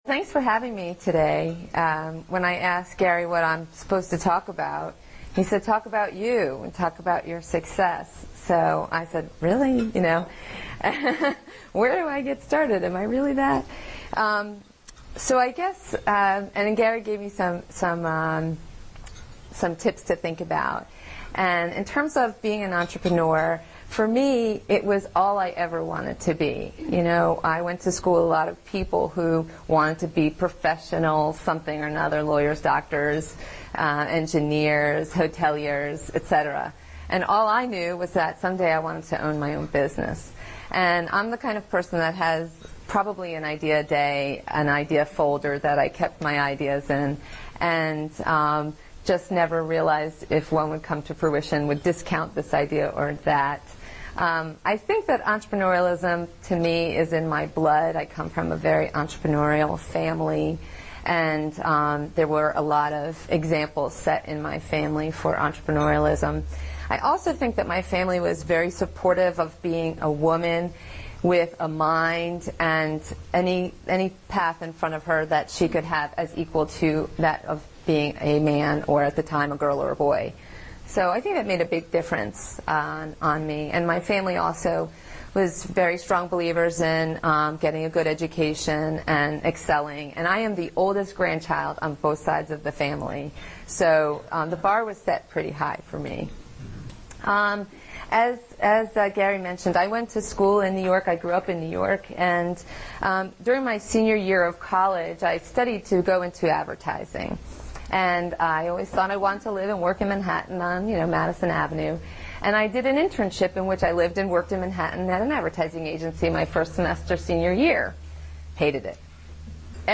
Presentation to the Baltimore-Harford Entrepreneurs Club.